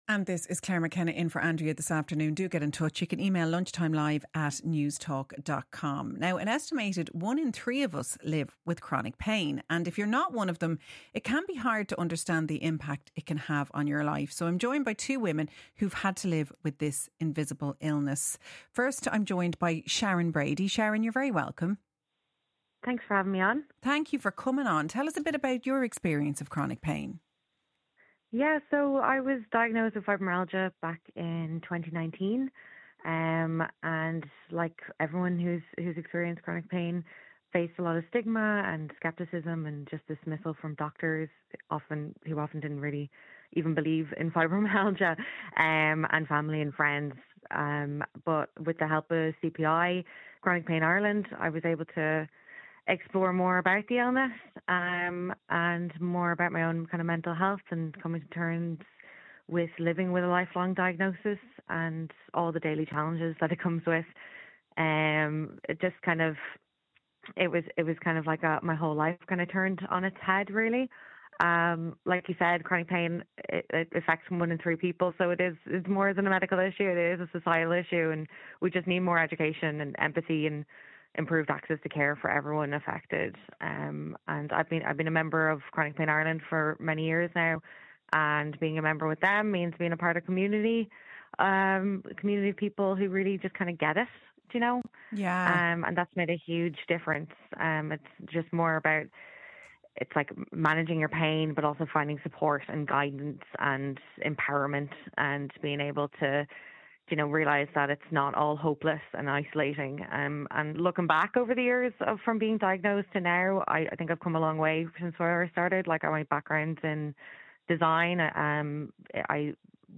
speaking on Newstalk Lunchtime Live